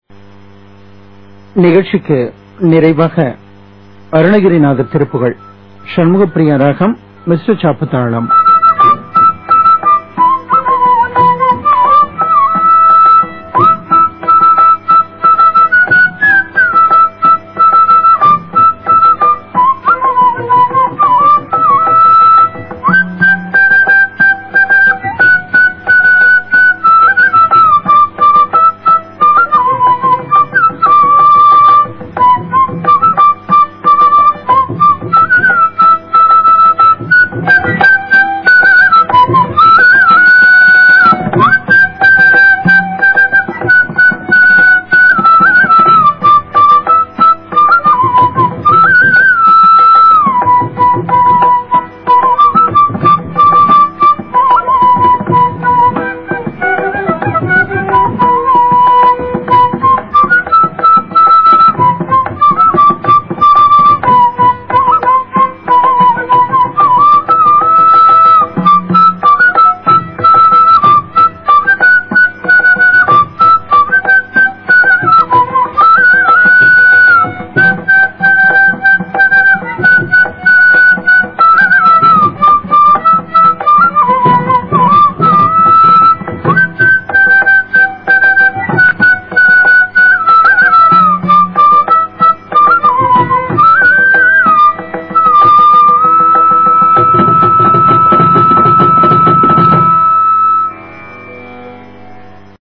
The whole concert
Flute
Violin
Mridangam